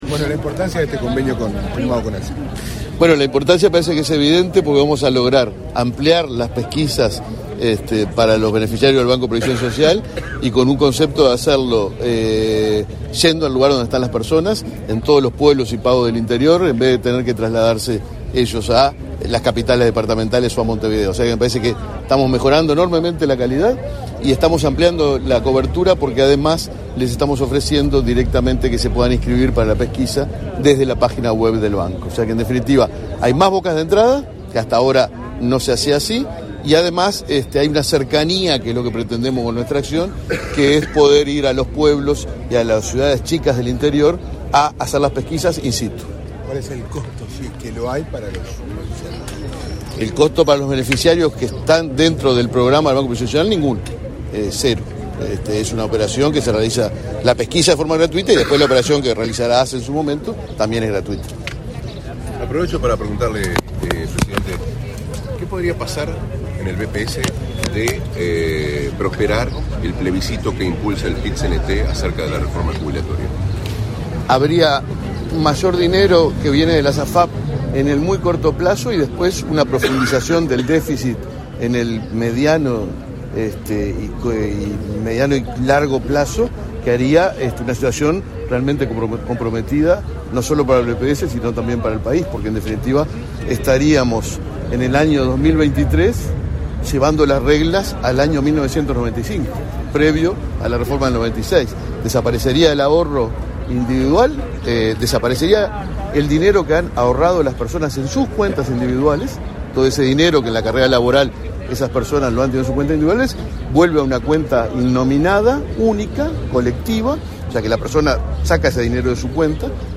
Declaraciones a la prensa del presidente del BPS, Alfredo Cabrera